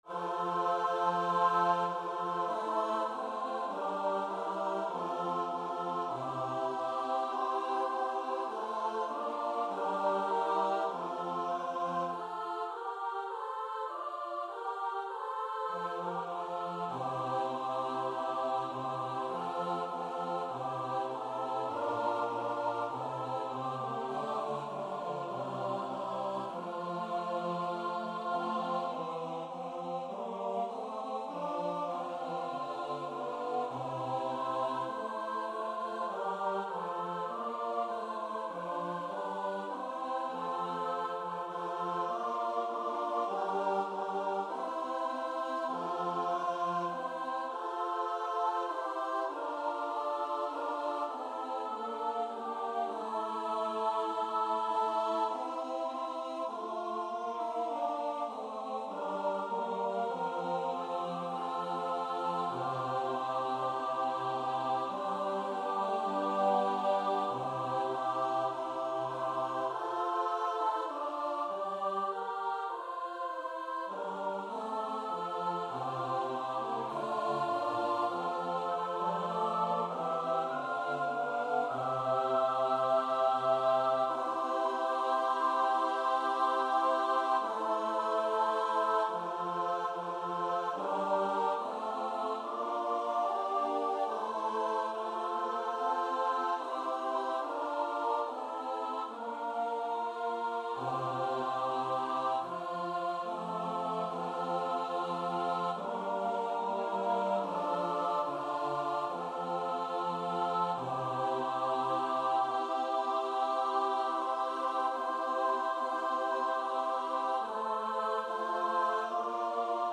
Gloria (Missa Brevis) Choir version
2/2 (View more 2/2 Music)
F major (Sounding Pitch) (View more F major Music for Choir )
Choir  (View more Intermediate Choir Music)
Classical (View more Classical Choir Music)